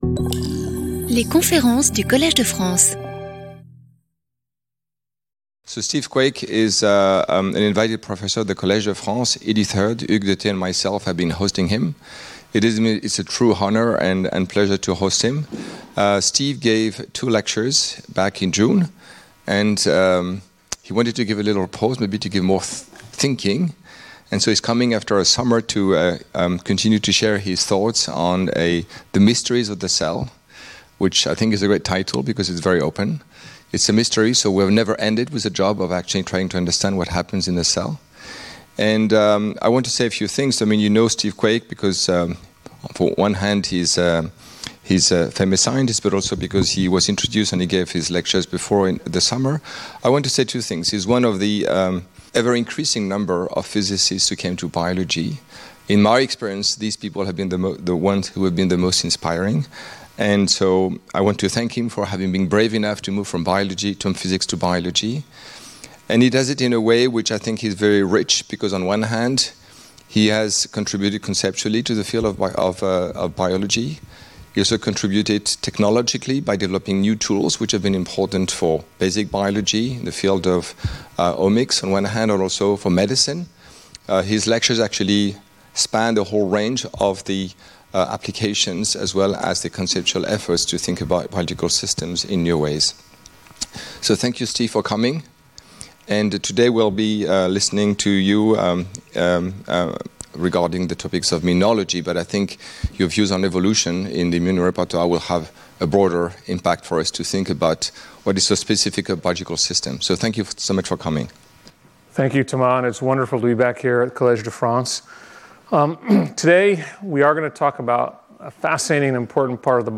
Bioengineering and Physics, Stanford University Events Previous Guest lecturer 12 May 2025 17:00 to 18:00 Stephen Quake Understanding the Mysteries of the Cell: How Do Many Cell Types Arise From One Genome?